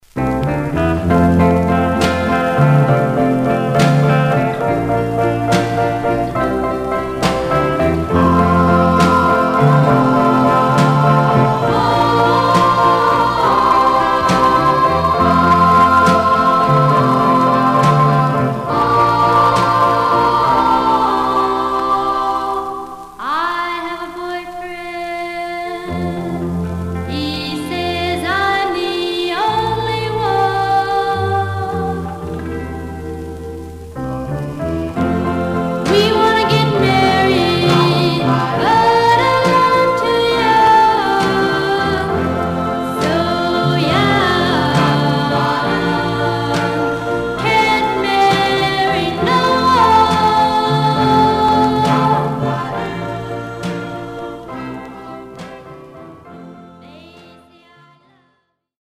Surface noise/wear
Mono
Black Female Group